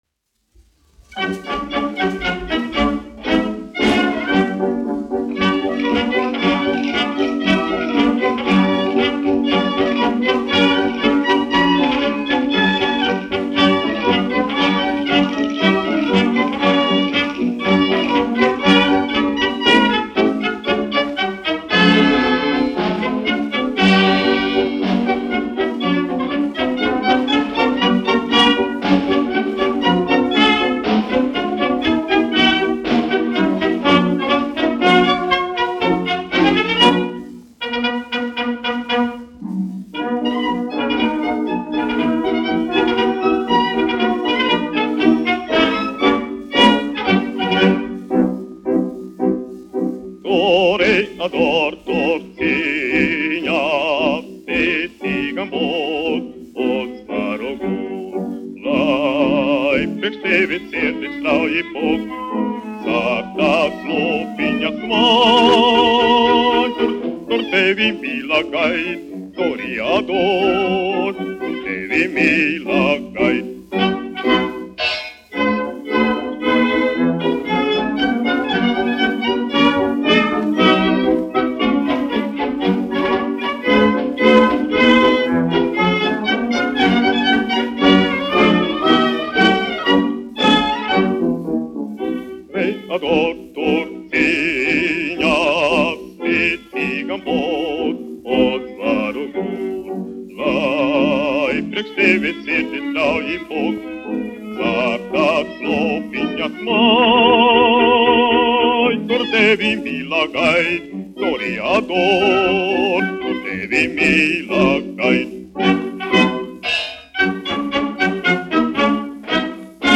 1 skpl. : analogs, 78 apgr/min, mono ; 25 cm
Orķestra mūzika, aranžējumi
Marši
Latvijas vēsturiskie šellaka skaņuplašu ieraksti (Kolekcija)